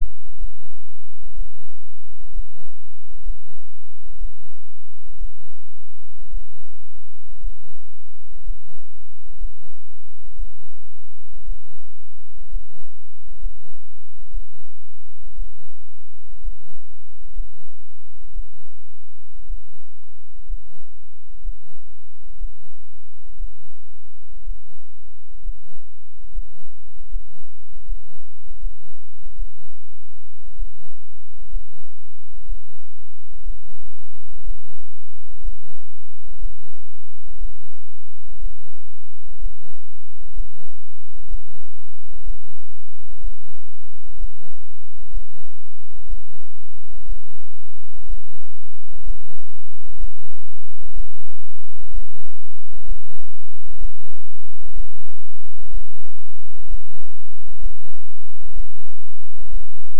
Fig. 1 shows two signals that were recorded at the same time but at different positions.
Fig. 1: MCG (magnetocardiogram) input signals.
MCG signals show the same cardiac cycle as known from ECG signals.